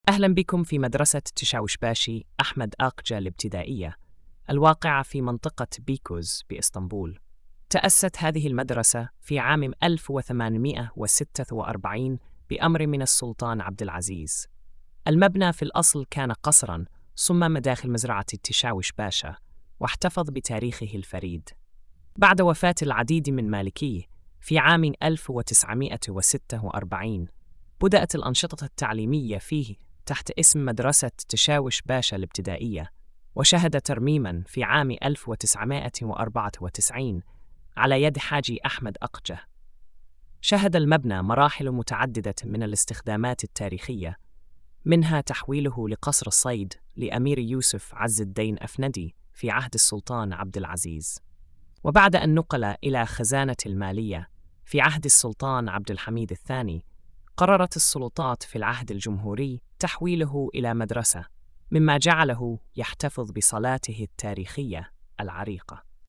السرد الصوتي: